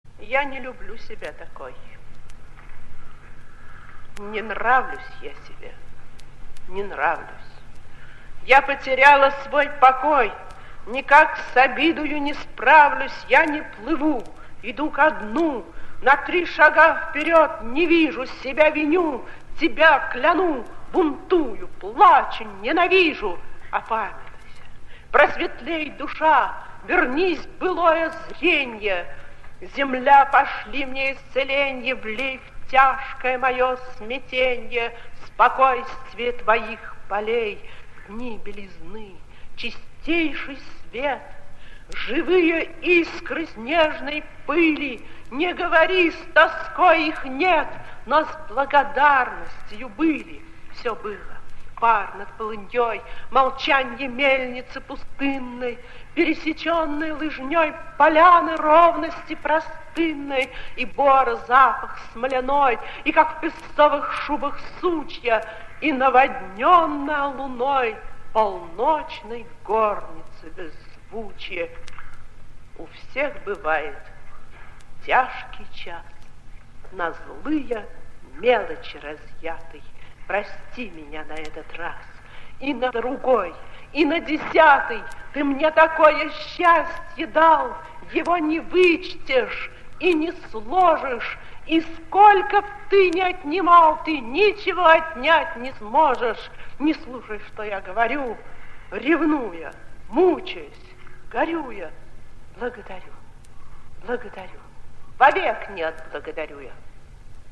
veronika-tushnova-raskayanie-chitaet-avtor